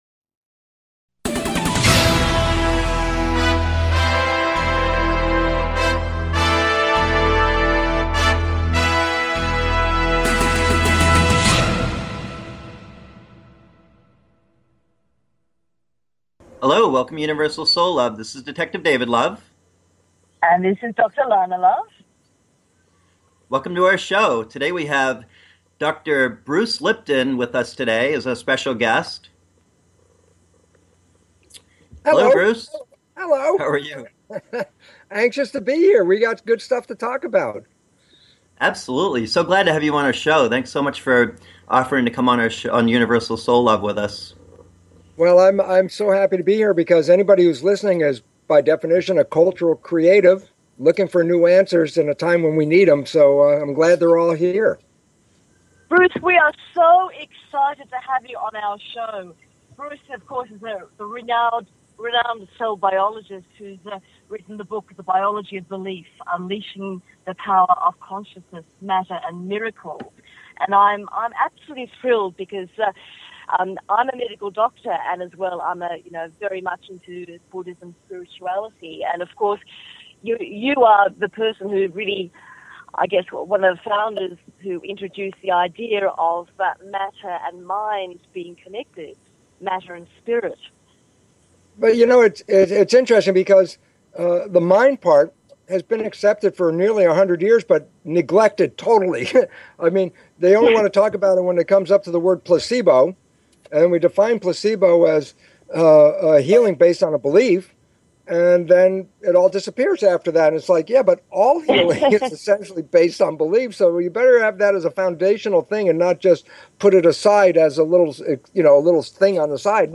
Guest, Bruce Lipton